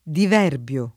vai all'elenco alfabetico delle voci ingrandisci il carattere 100% rimpicciolisci il carattere stampa invia tramite posta elettronica codividi su Facebook diverbio [ div $ rb L o ] s. m.; pl. ‑bi (raro, alla lat., -bii )